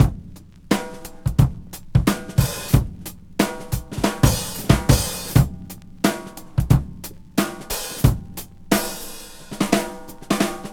• 89 Bpm Breakbeat Sample B Key.wav
Free breakbeat sample - kick tuned to the B note. Loudest frequency: 1937Hz
89-bpm-breakbeat-sample-b-key-Lt2.wav